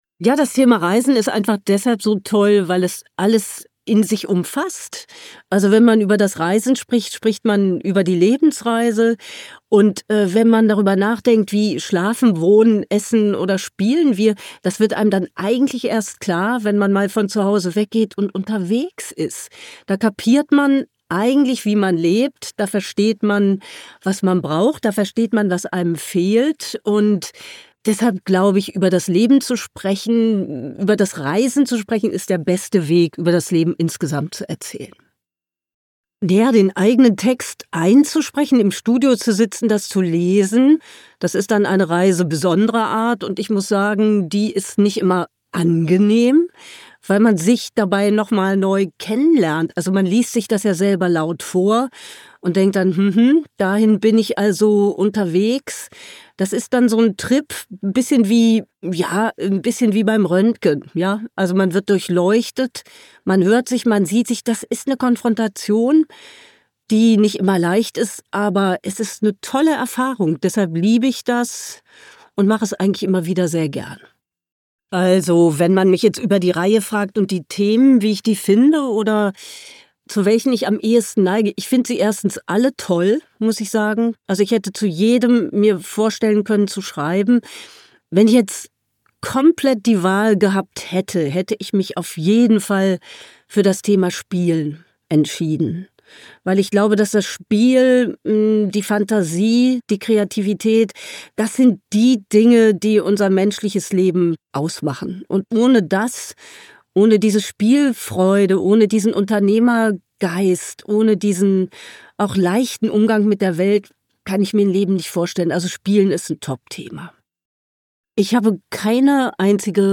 Hoppe_Interview_Reisen.mp3